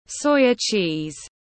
Đậu phụ tiếng anh gọi là soya cheese, phiên âm tiếng anh đọc là /ˈsɔɪ.ə tʃiːz/
Soya cheese /ˈsɔɪ.ə tʃiːz/